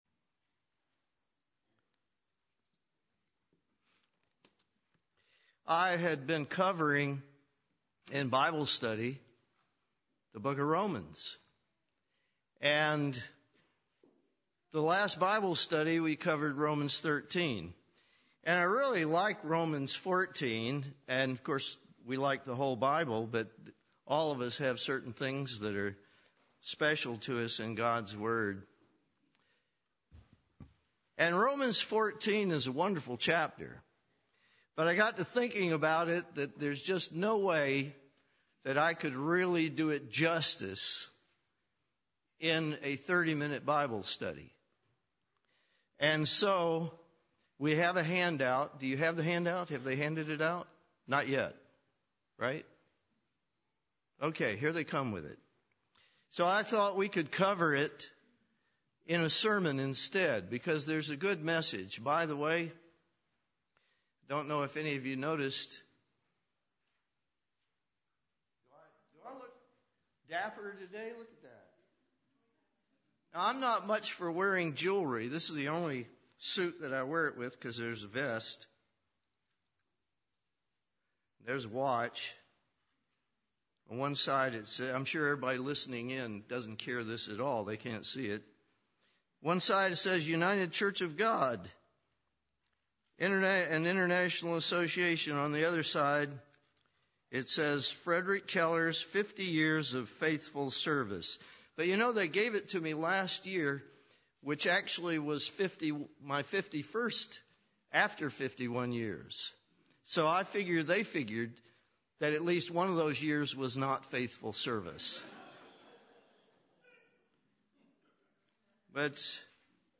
A in-depth Bible study covering Romans 14.
Given in Nashville, TN
UCG Sermon Studying the bible?